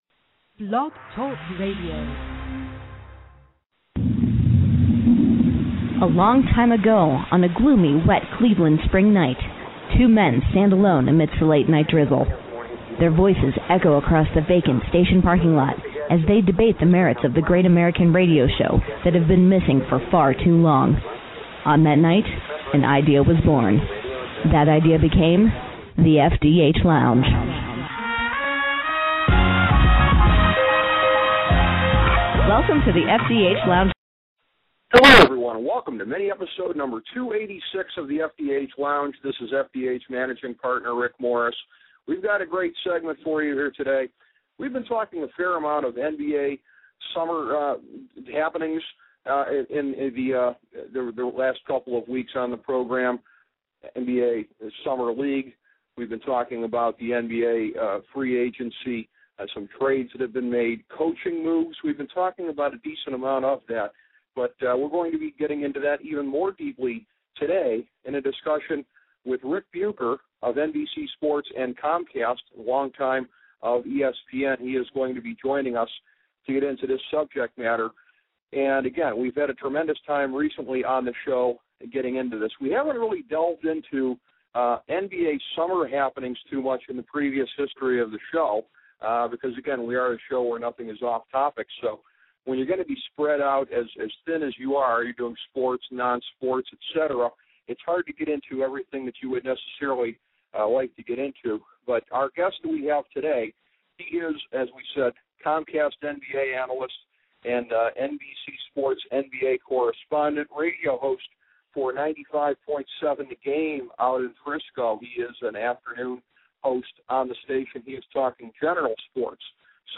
A conversation with Ric Bucher
In another appearance on the Sportsology channel, The FDH Lounge sits down with Comcast NBA analyst Ric Bucher.